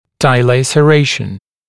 [daɪˌleɪsə’reɪʃ(ə)n][дайˌлэйсэ’рэйш(э)н]дилацерация, деформация или изгиб корня зуба